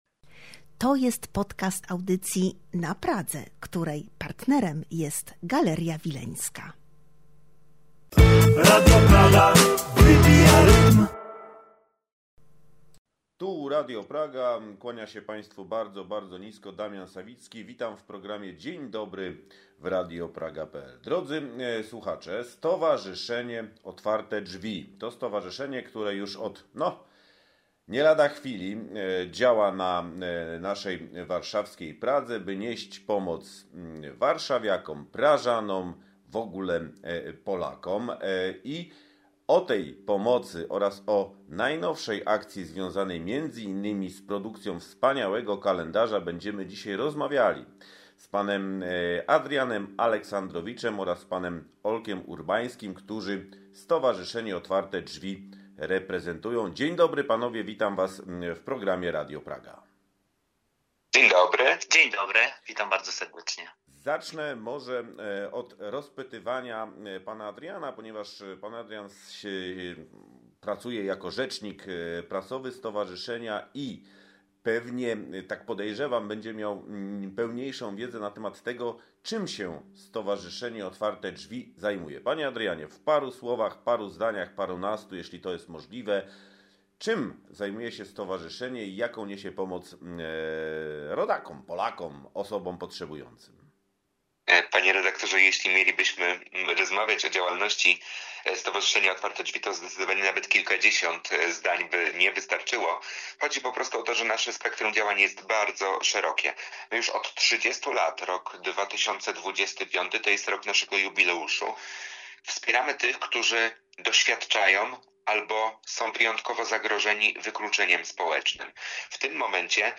Nasze radio miało wspaniałą okazję uczestniczyć w spotkaniu inaugurującym wystawę oraz porozmawiać z inicjatorami pokazu: